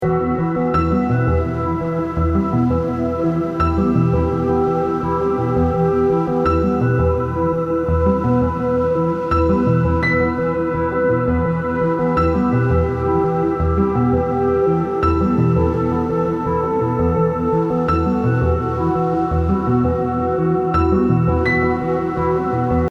528hz BPM80-89 calm Game Instrument Soundtrack インストルメント 穏やか
BPM 84